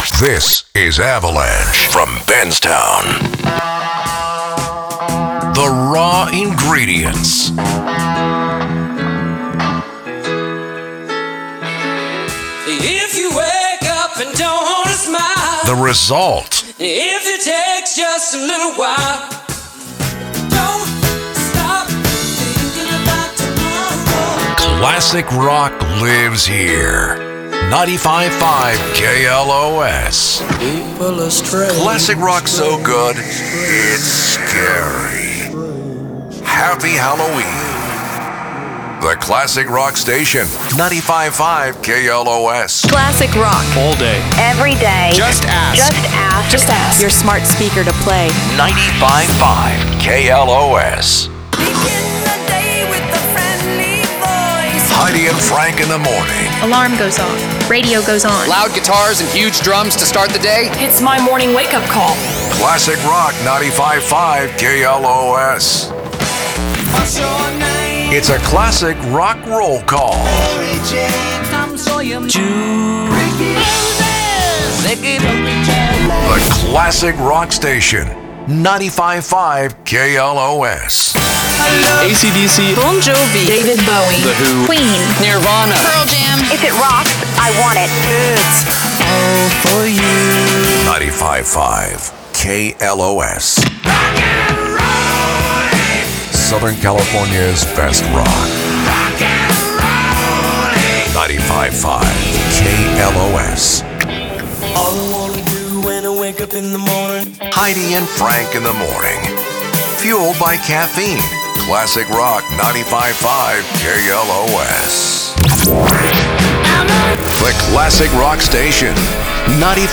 CLASSIC ROCK
Containing shelled produced promos and sweepers, ramploops, branded song intros, artist IDs, listener drops, song hooks, musicbeds, individual imaging workparts, and more.